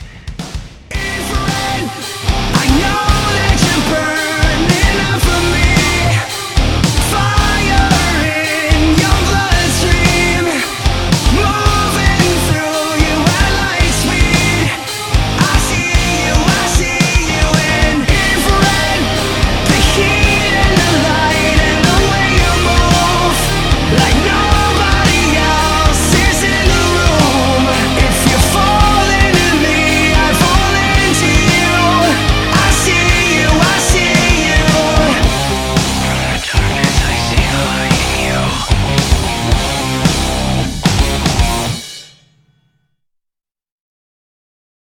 Kategória: Rock
Minőség: 320 kbps 44.1 kHz Stereo